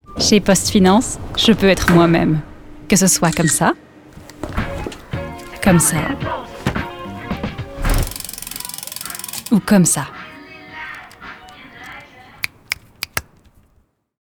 Sprecherin mit breitem Einsatzspektrum.